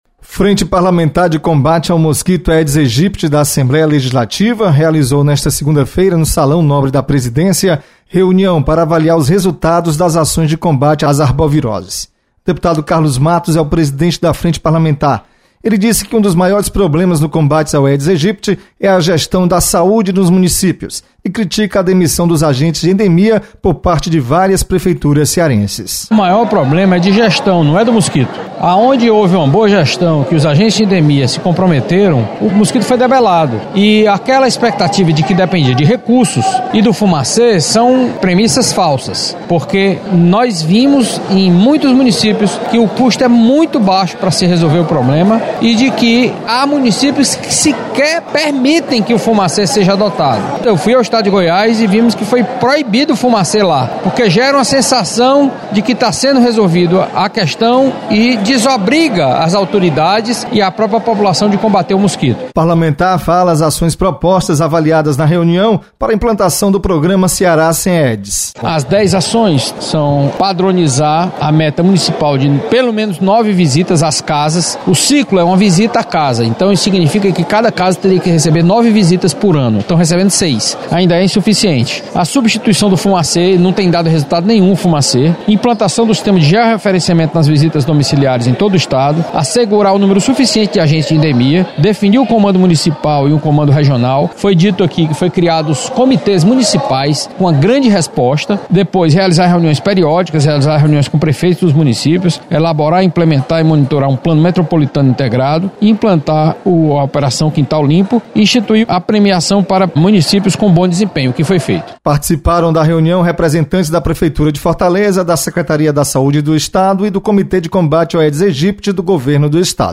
Deputado Carlos Matos cobra melhor gestão das prefeituras no combate ao Aedes aegypti. Repórter